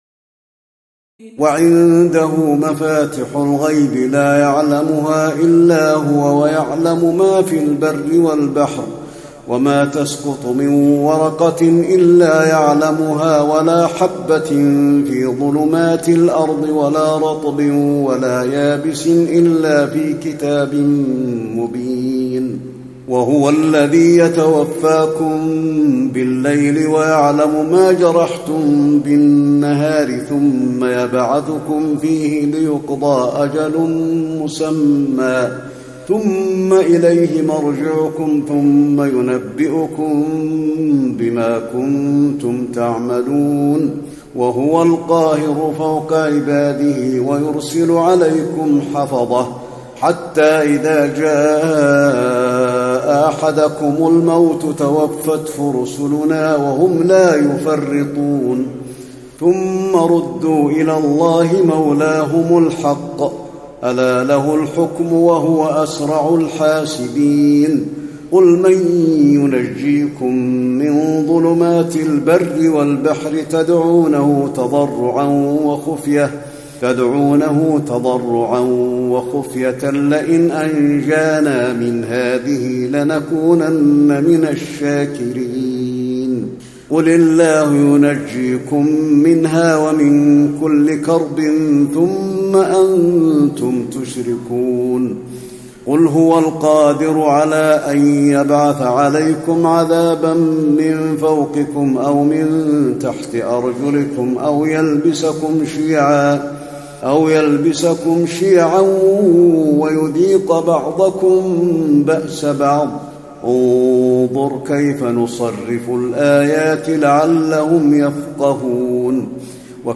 تراويح الليلة السابعة رمضان 1435هـ من سورة الأنعام (59-140) Taraweeh 7 st night Ramadan 1435H from Surah Al-An’aam > تراويح الحرم النبوي عام 1435 🕌 > التراويح - تلاوات الحرمين